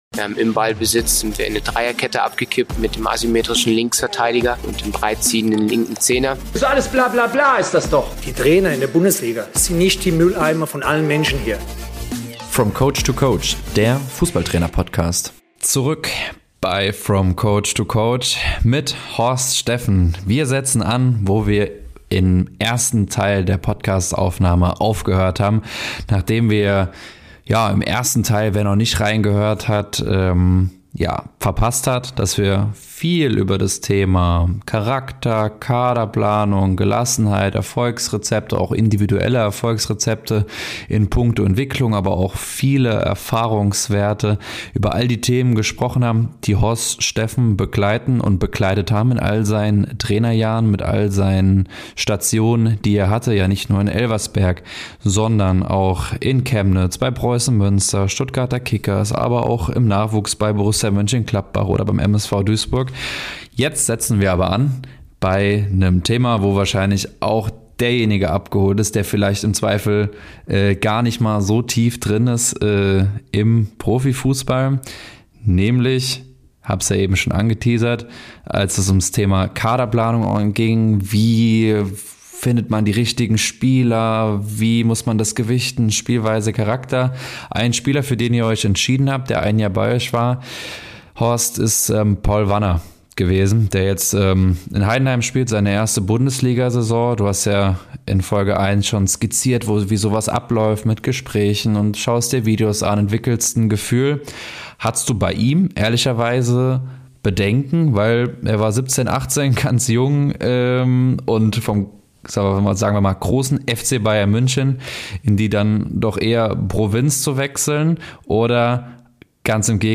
Dies und viel mehr verrät Horst Steffen im zweiten Teil des Podcast-Interviews.